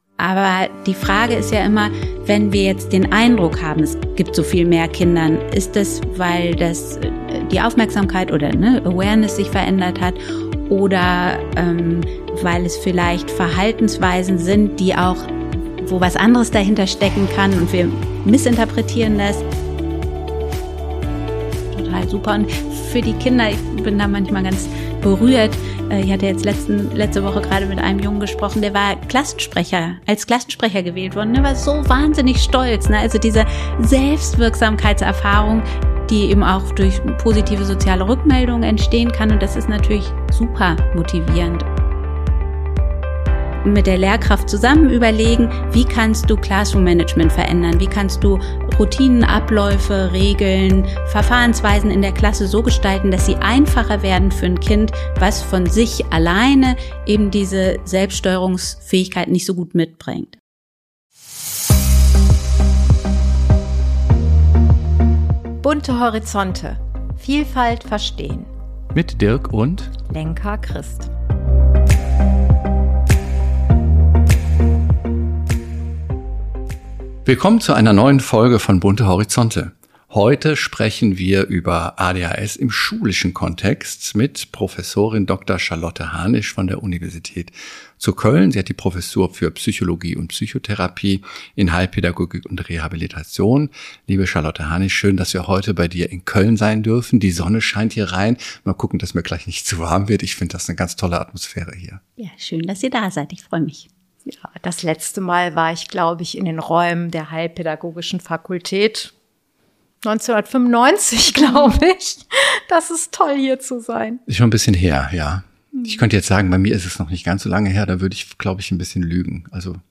Ein Gespräch, das Orientierung gibt und deutlich macht: Kinder mit ADHS profitieren dort am stärksten, wo Schule, Familie und Therapie zusammenarbeiten.